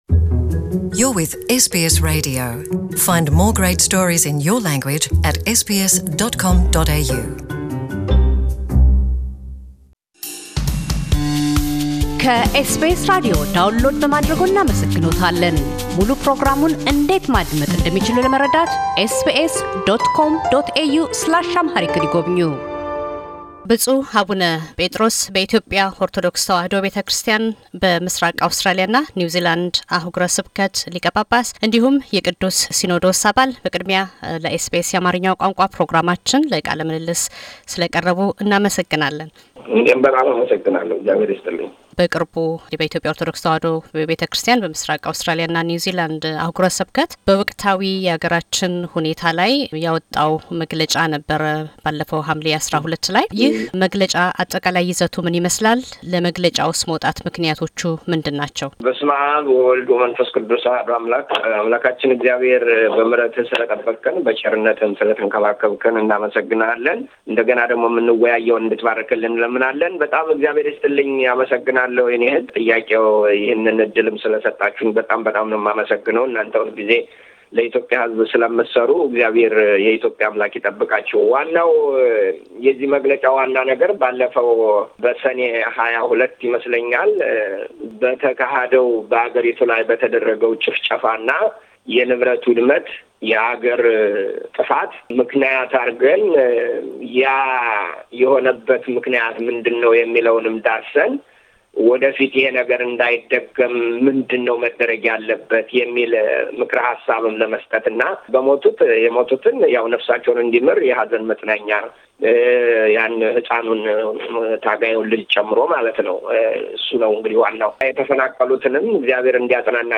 ብፁዕ አቡነ ጴጥሮስ - በኢትዮጵያ ኦርቶዶክስ ተዋሕዶ ቤተክርስቲያን የምሥራቅ አውስትራሊያና ኒውዝላንድ አሕጉረ ስብከት ሊቀ ጳጳስና የቅዱስ ሲኖዶስ አባል፤ በቅርቡ የአገረ ስብከት ጽሕፈት ቤቱ ያወጣውን መግለጫ አስመልክተው ይናገራሉ።